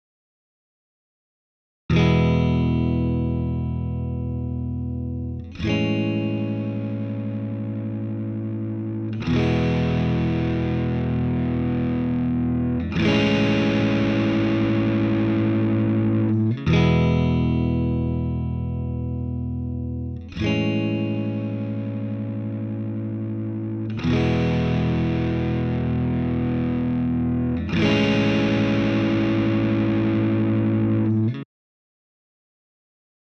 Overdrive
Overdrive.mp3